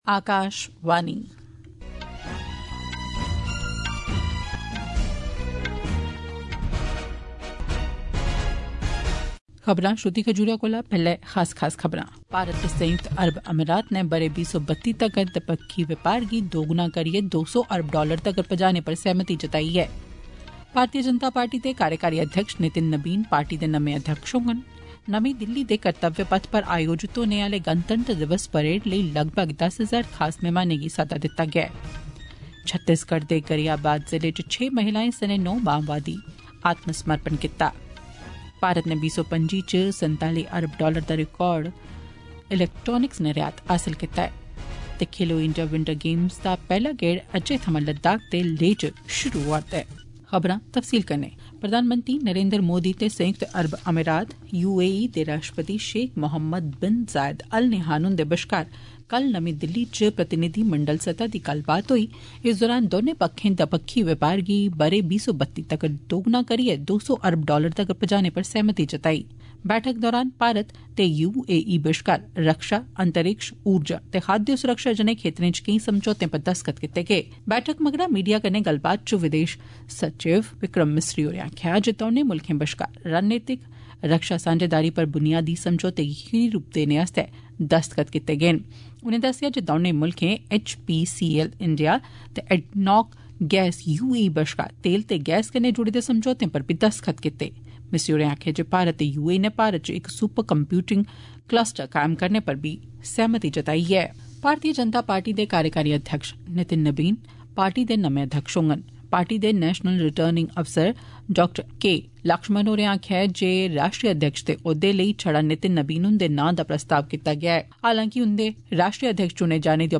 AUDIO-OF-MORNING-DOGRI-NEWS-BULLETIN-NSD-9.mp3